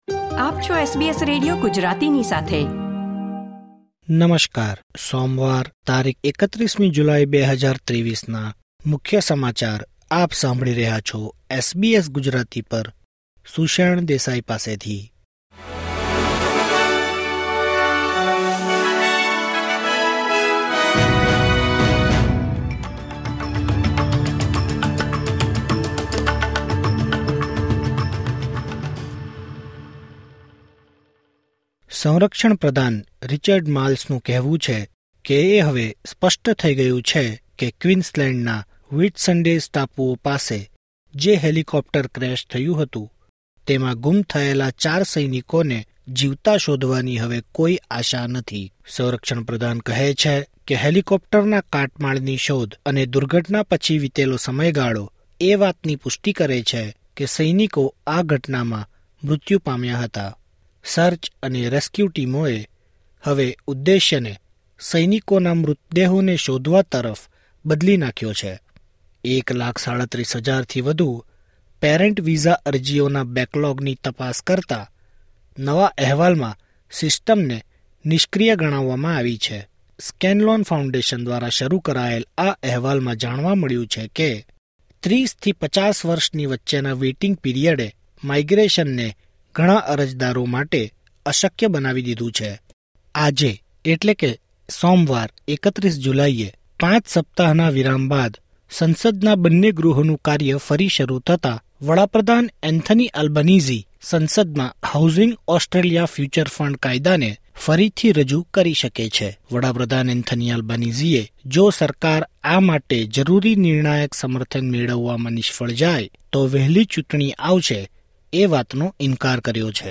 SBS Gujarati News Bulletin 31 July 2023